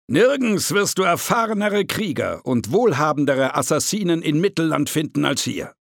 Voice-Over Recordings